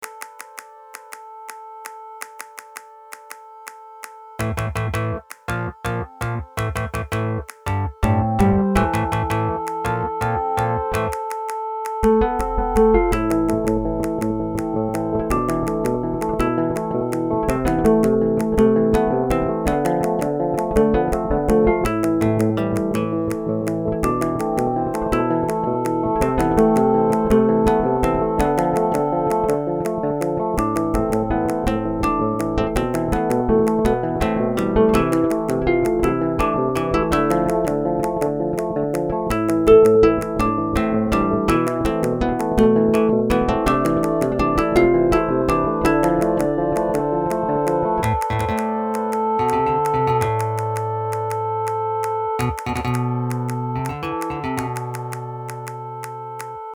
Key Instruments: Guitar, Synth, Finger Snaps